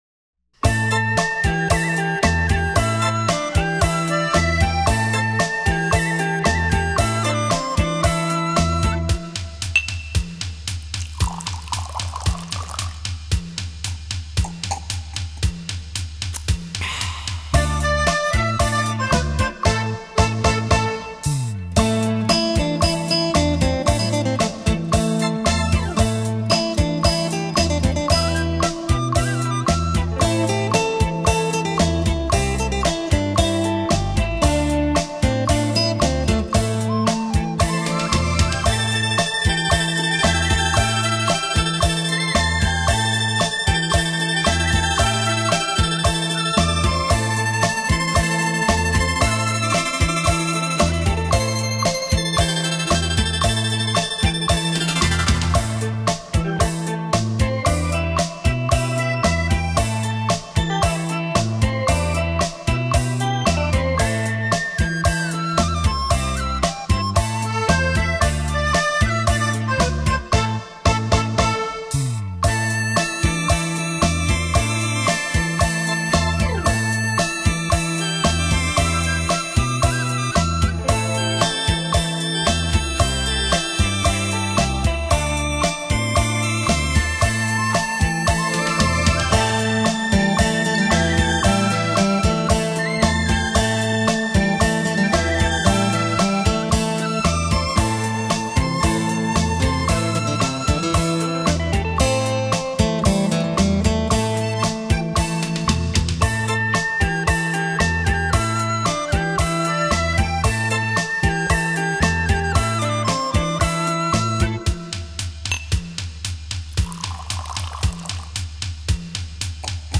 山地舞曲
最原始的旋律
尽情欢乐